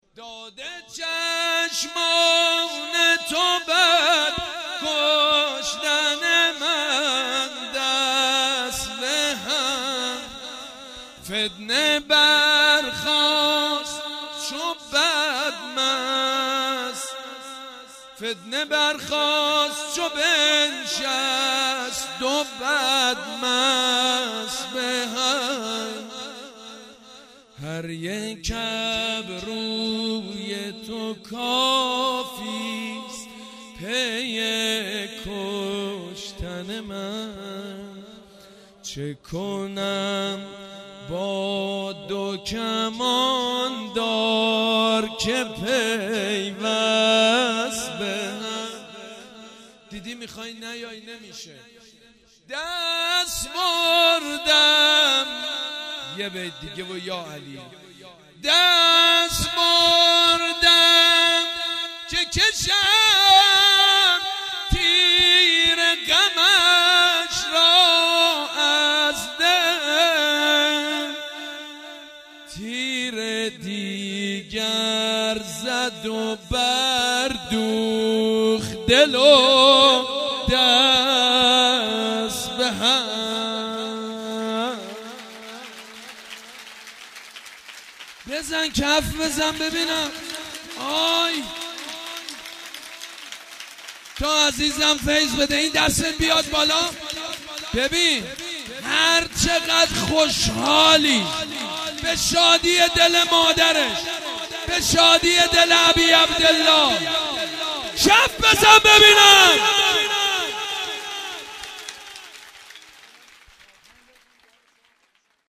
مدح: داد چشمان تو در كشتن من دست به هم
مدح: داد چشمان تو در كشتن من دست به هم خطیب: سید مجید بنی فاطمه مدت زمان: 00:01:55